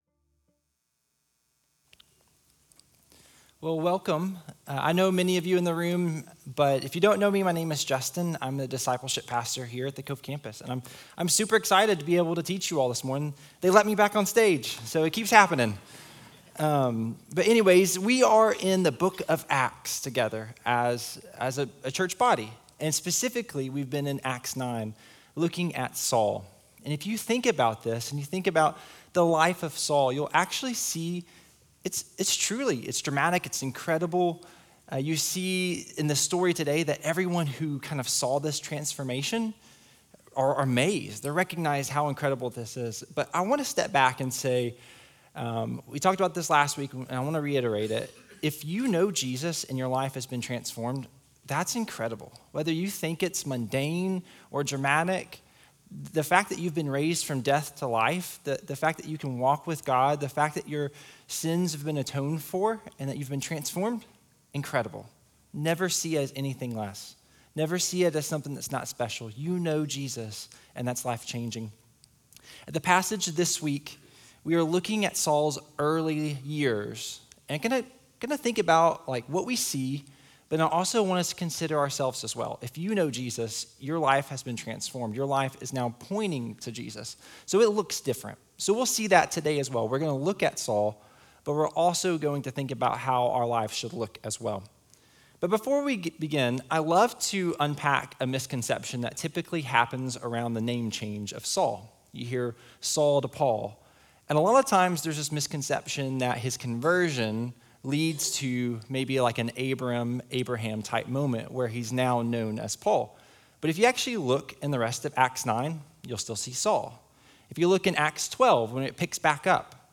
Sermon Audio Sermon Notes…